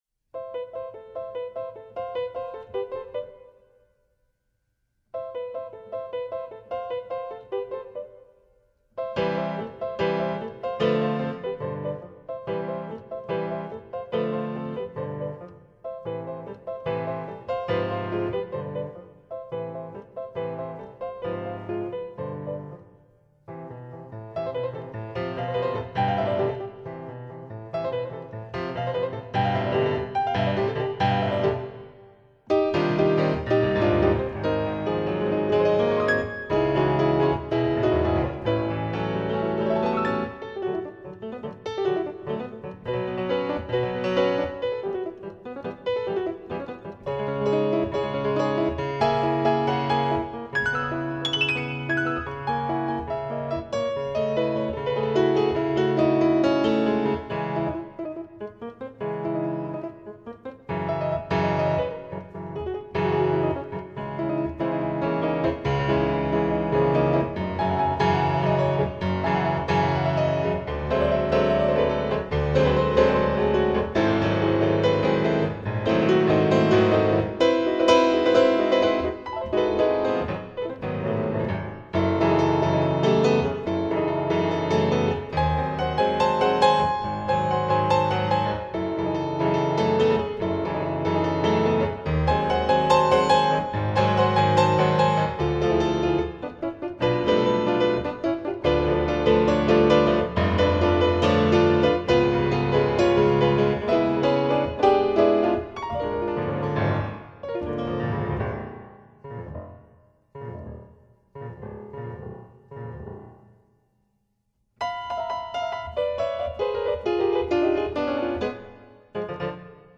mezzo di esecuzione: pianoforte